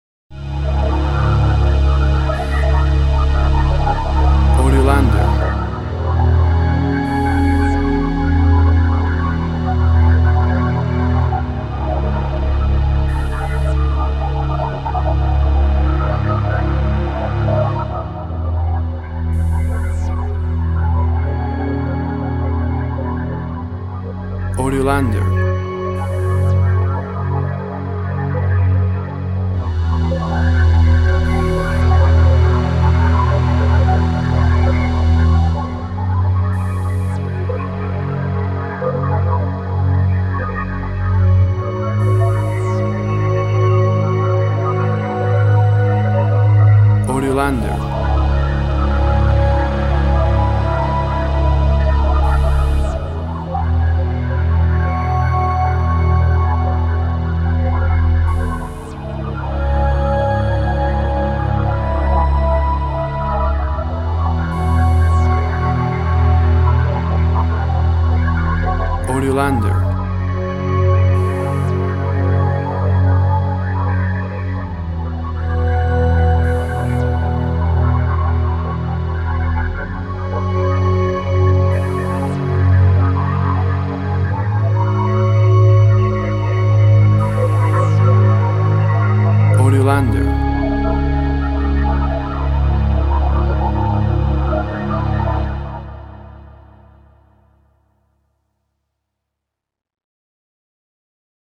Tempo (BPM) 136